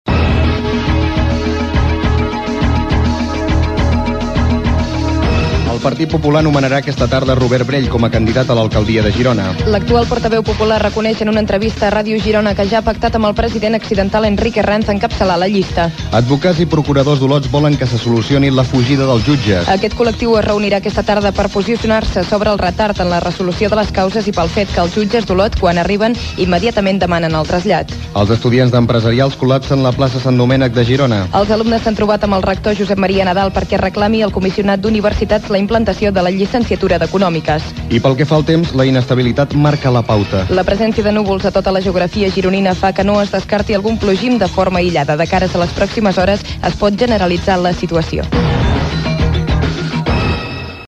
Informatius: titulars